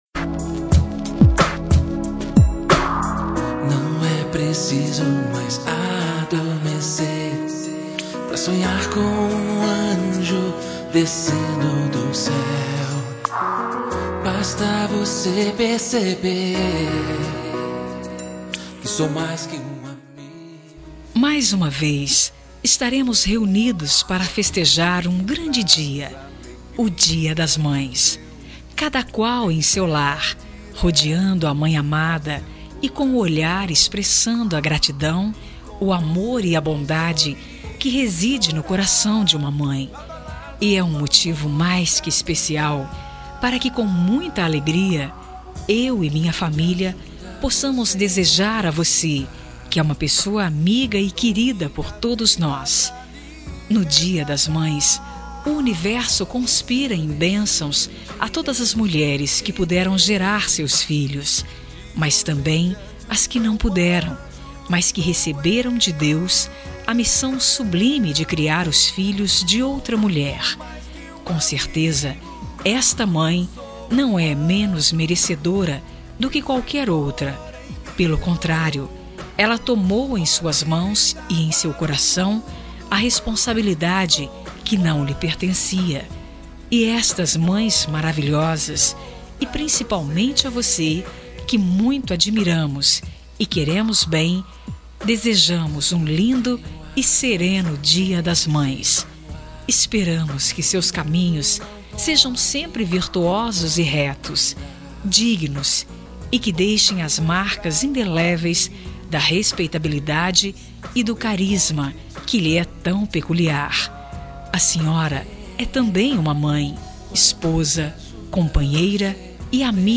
01.Dia-das-Maes-Amiga-Feminino-MAIS-QUE-AMIGOS-1-1.mp3